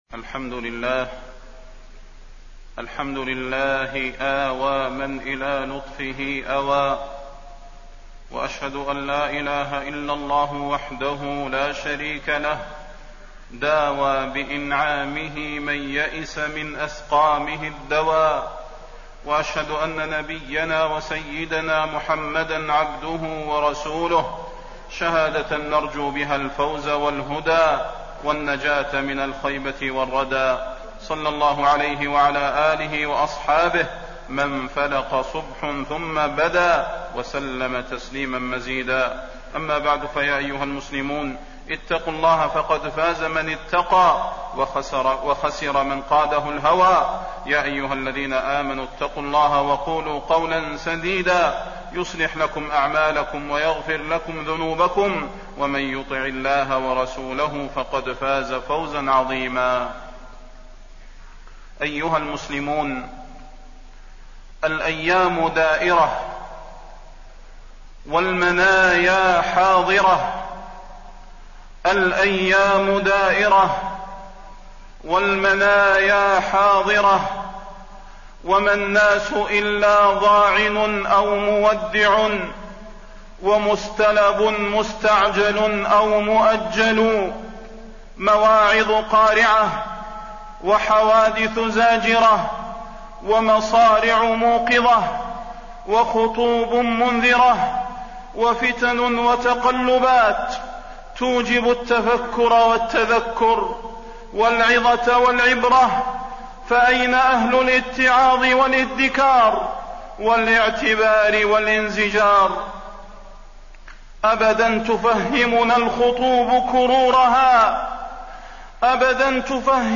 فضيلة الشيخ د. صلاح بن محمد البدير
تاريخ النشر ١٨ شوال ١٤٣٢ هـ المكان: المسجد النبوي الشيخ: فضيلة الشيخ د. صلاح بن محمد البدير فضيلة الشيخ د. صلاح بن محمد البدير الأيام دائرة